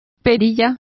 Complete with pronunciation of the translation of goatee.